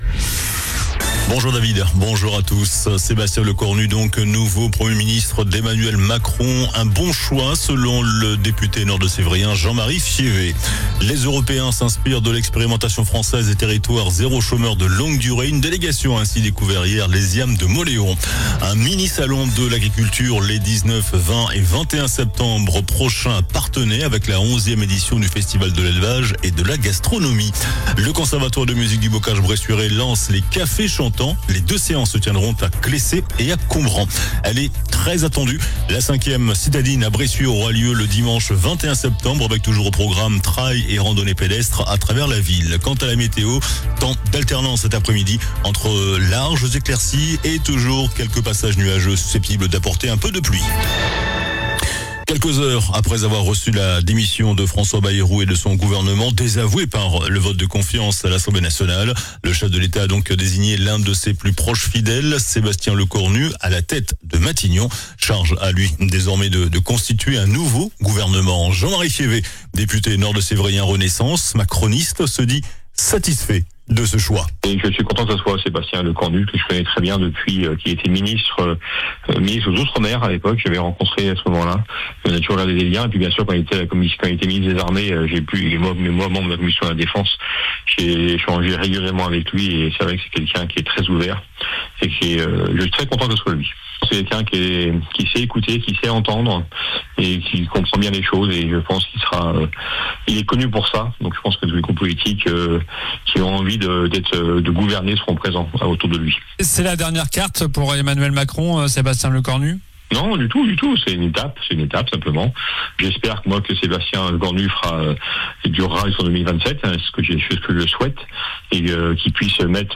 JOURNAL DU JEUDI 11 SEPTEMBRE ( MIDI )